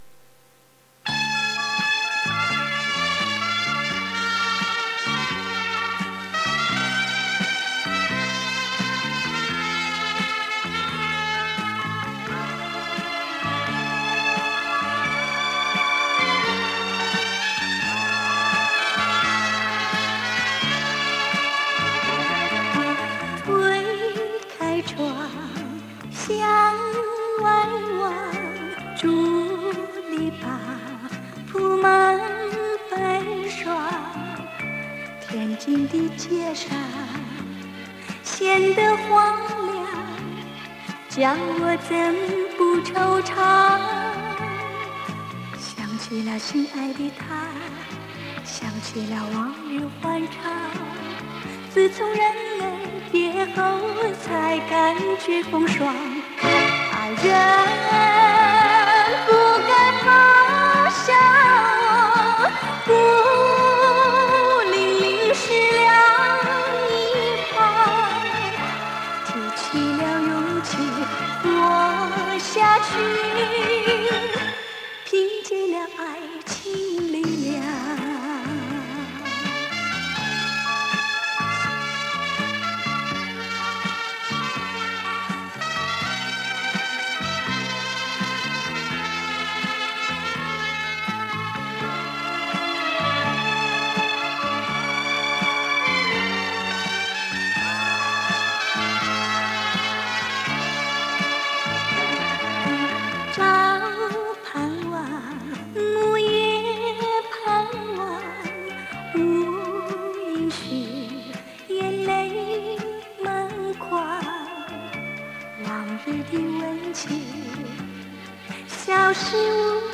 磁带数字化：2022-07-28